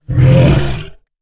horror
Monster Growl 15